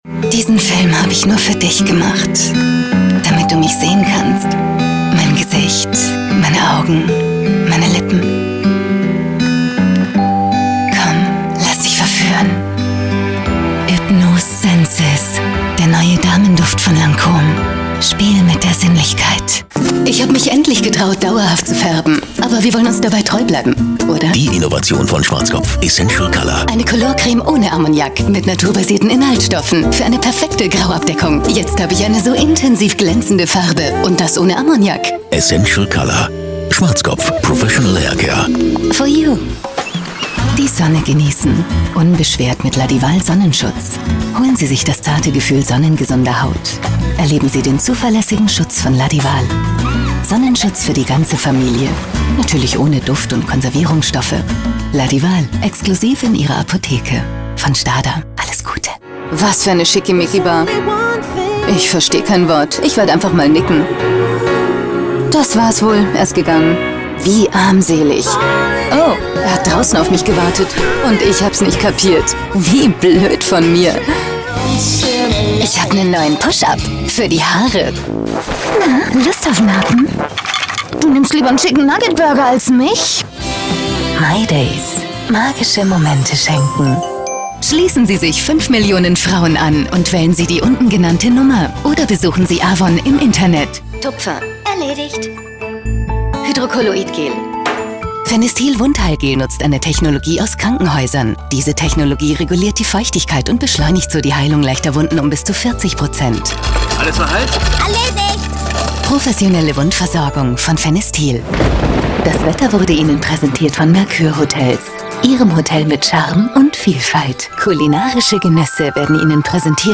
profiSprecherin - voiceArtist - ISDN-Studio - Stationvoice, TV-Offsprecherin
Sprechprobe: Werbung (Muttersprache):
german female voice over artist with professional ISDN-studio - Stationvoice, well-known TV-Voice, warm, believable german voicetalent, Off-voice, VoiceOver, radiospots, audiobooks and more.